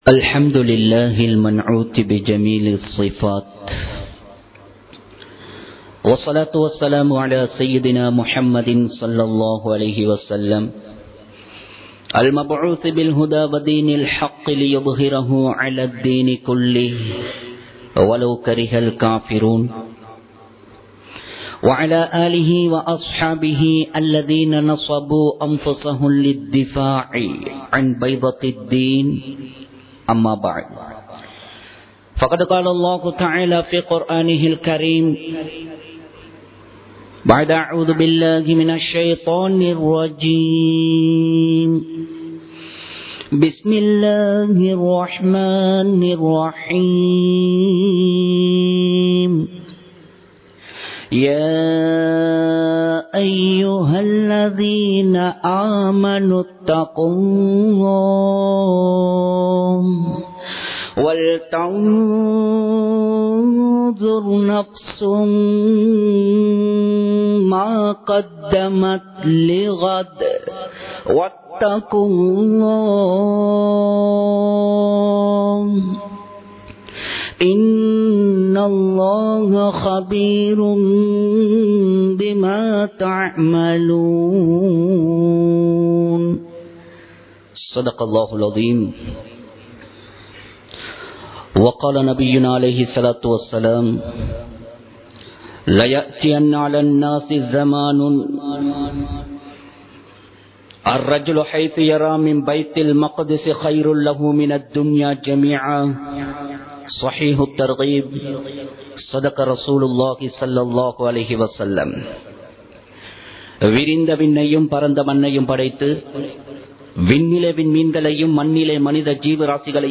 முஸ்லிம்கள் சோதிக்கப்பட 02 காரணங்கள் | Audio Bayans | All Ceylon Muslim Youth Community | Addalaichenai
Muhiyadeen Jumua Masjith